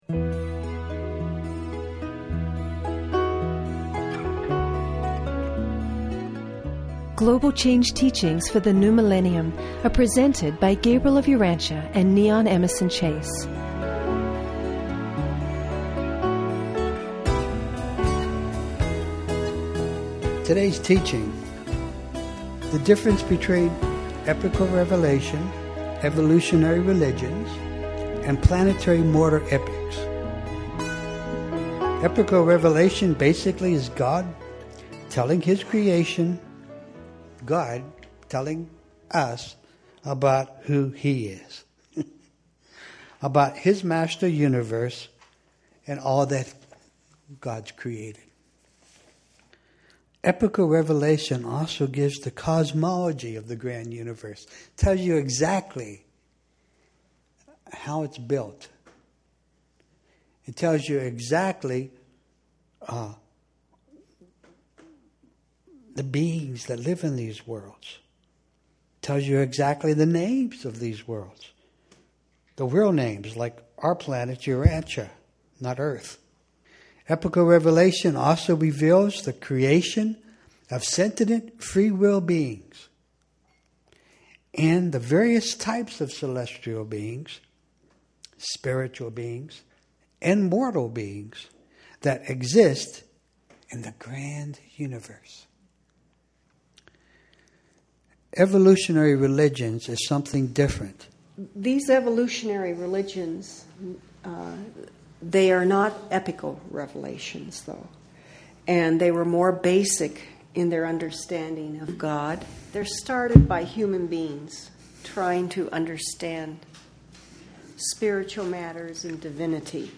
A discourse on what constitutes revealed religion vs. evolved religion, and the intervening time bet…